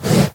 should be correct audio levels.
breathe3.ogg